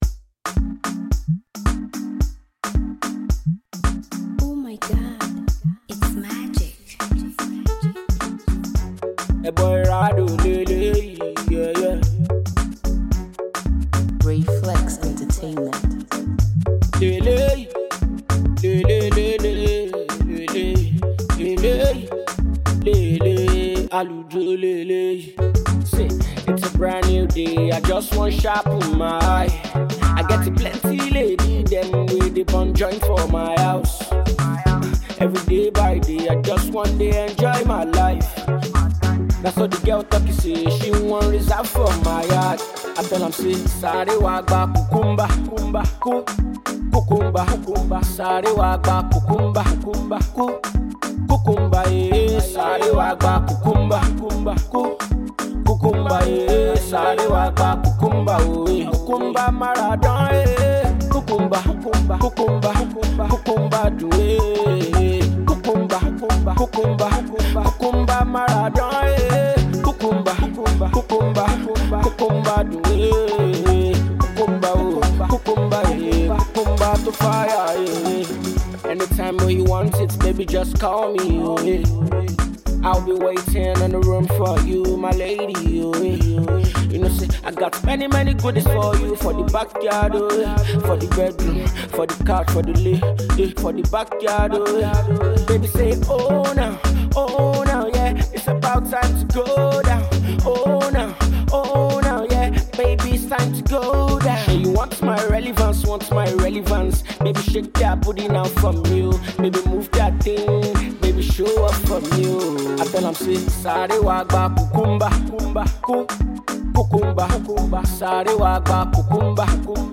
a whole new sound
a smooth melody that will definitely make your body rock.